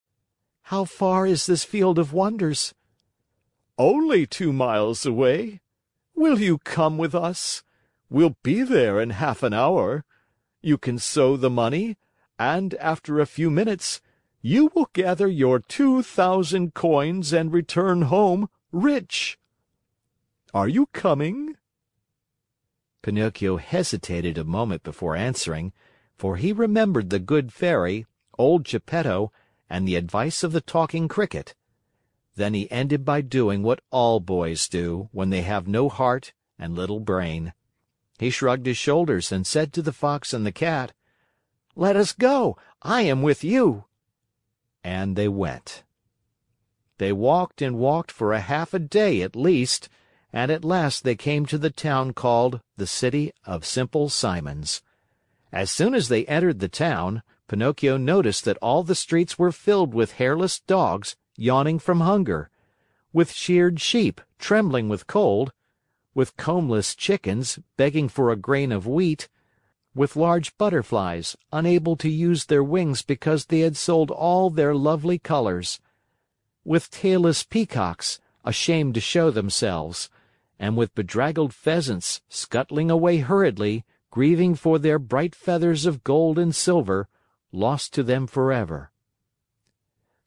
在线英语听力室木偶奇遇记 第53期:狐狸和猫(4)的听力文件下载,《木偶奇遇记》是双语童话故事的有声读物，包含中英字幕以及英语听力MP3,是听故事学英语的极好素材。